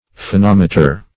Search Result for " phonometer" : The Collaborative International Dictionary of English v.0.48: Phonometer \Pho*nom"e*ter\, n. [Phono- + -meter.] (Physics) An instrument for measuring sounds, as to their intensity, or the frequency of the vibrations.